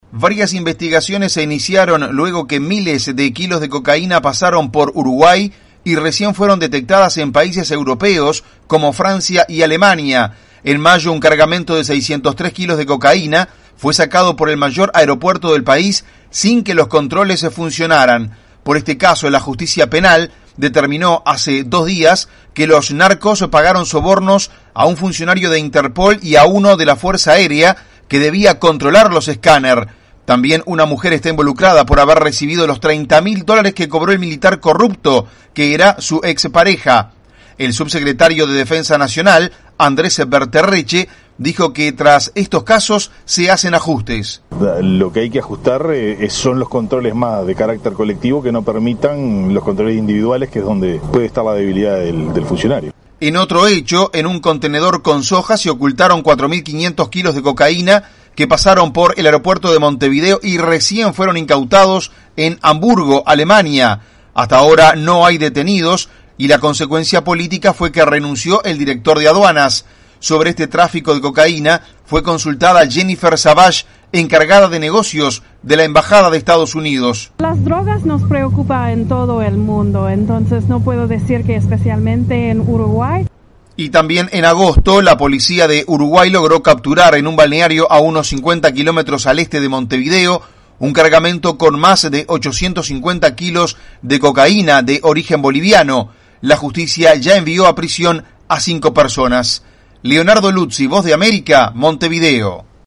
VOA: Informe desde Uruguay